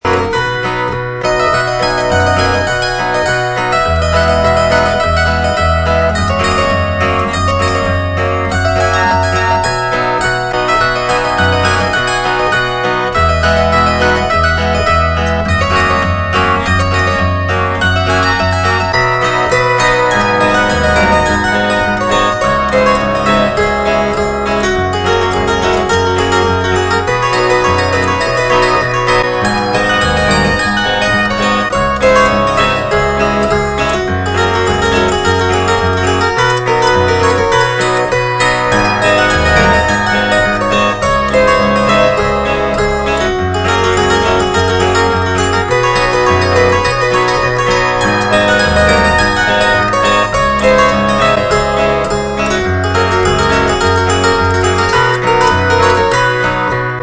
Phonoharp/Columbia No. 4 chord-zither
And another zither solo, a country ragtime piece entitled "Japanese Breakdown", played on the 6/22 chord-zither pictured, a Columbia Special #4.